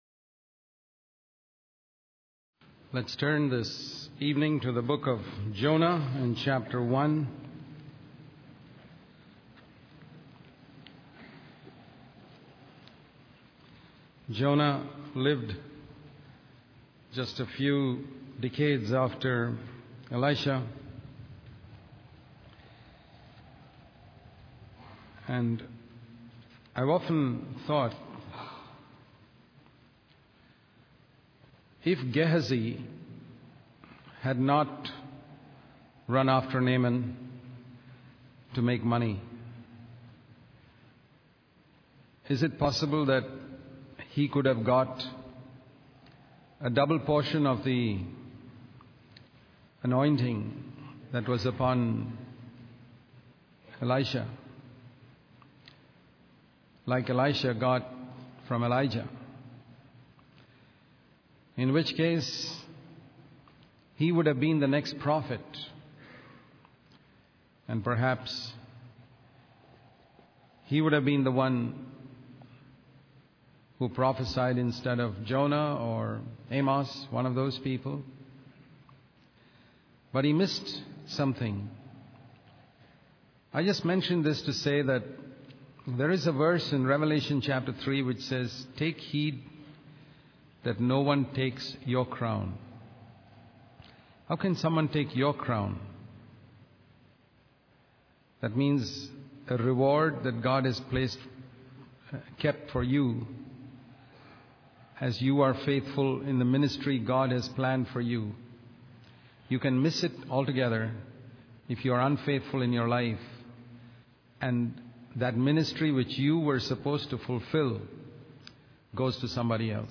In this sermon, the speaker discusses God's controversy with His people as described in Micah chapters six and seven.